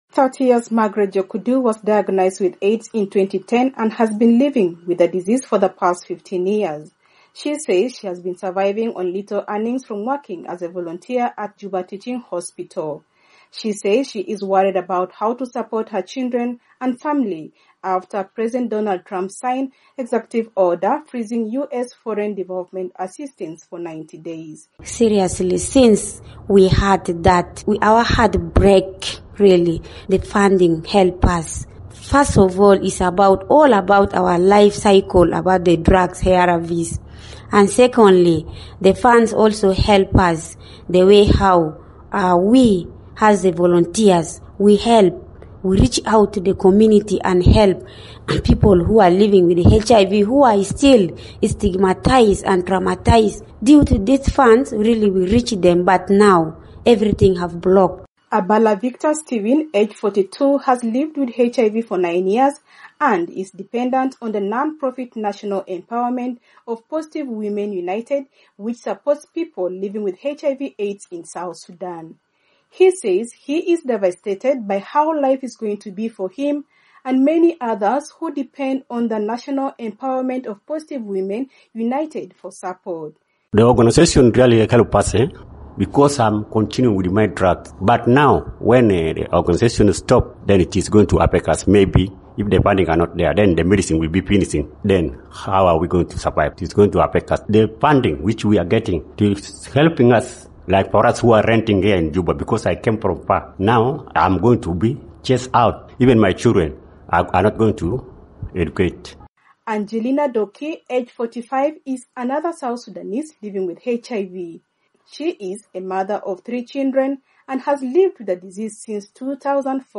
reports from Juba.